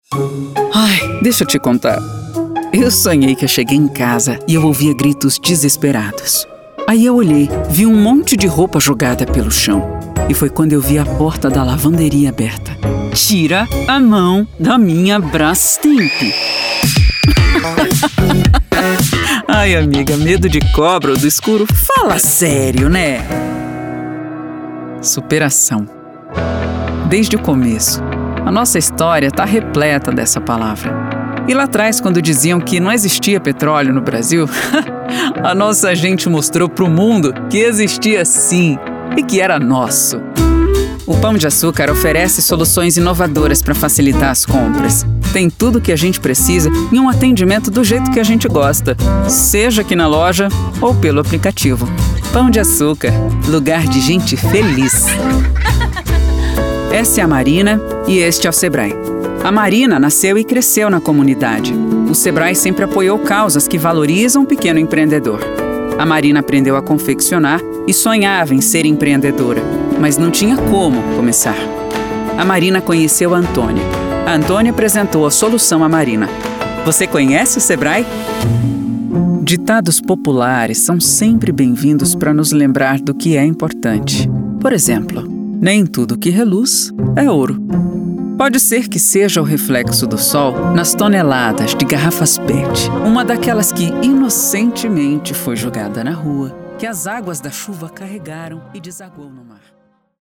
Sprechprobe: Werbung (Muttersprache):
Woman's voice, with peculiar and differentiated timbre. Voice that conveys credibility and confidence in a natural way. Neutral accent, which suits the client's needs and requirements. Elegant, friendly, mother, teacher, witch, neighborhood.